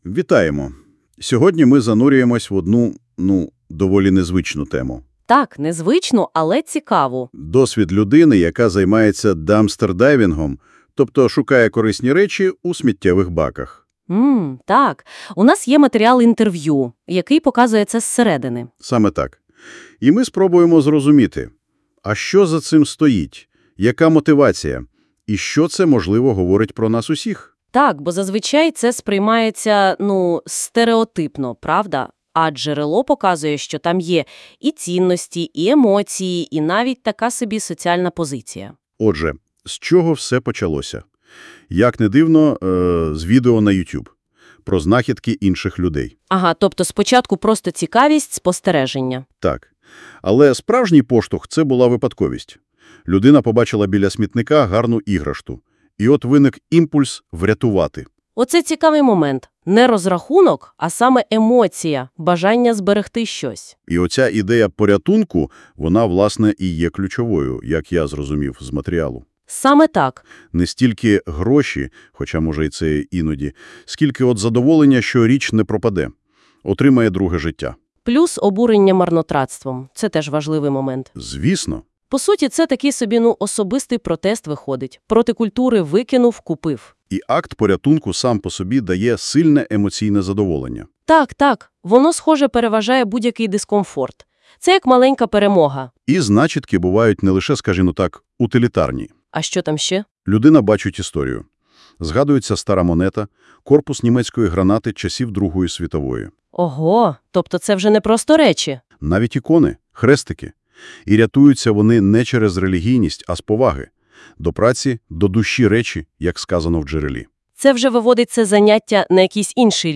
Підкаст за мотивами інтерв’ю:
Інтервю-про-Дампстердайвінг-та-Порятунок-Речей.wav